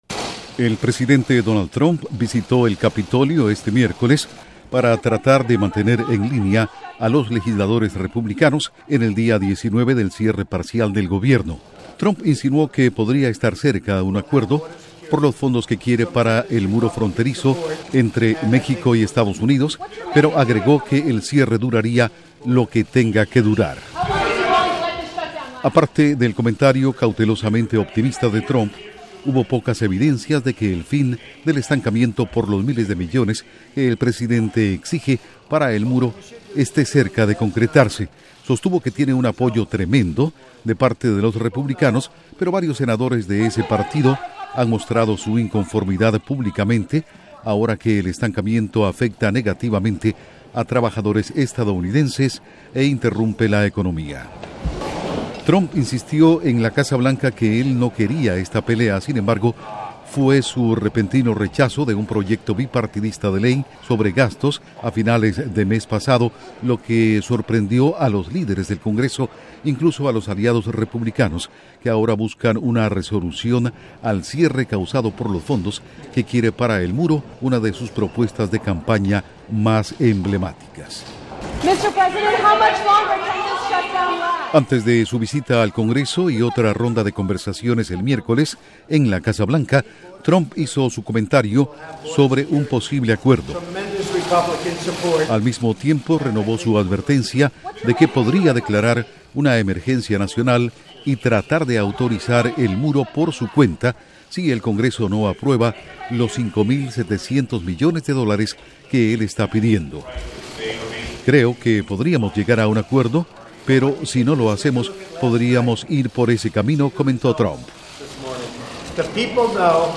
Trump visita republicanos en el Congreso en busca de apoyo para construcción del muro fronterizo; el gobierno sigue parcialmente cerrado. Informa desde la Voz de América en Washington
Duración: 2:05 Audios de Trump en el Capitolio